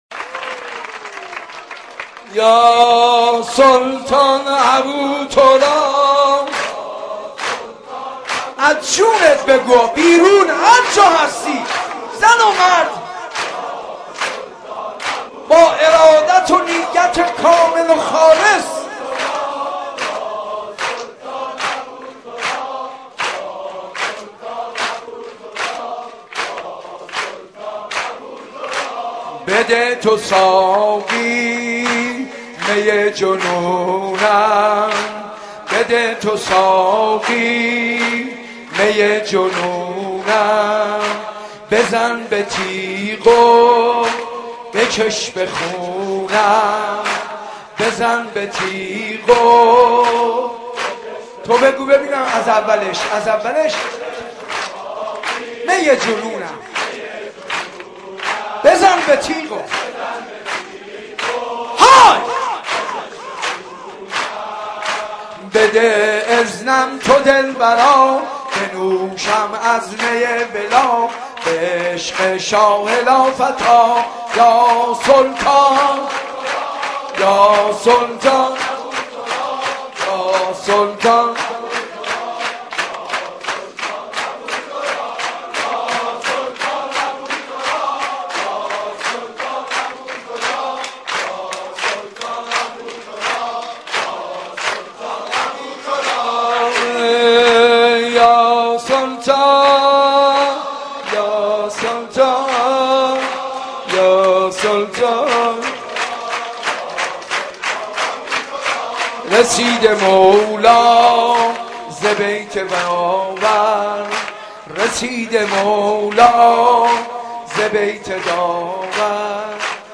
مراسم میلاد امام علی
حسینیه صنف لباسفروشها, حسینیه چمنی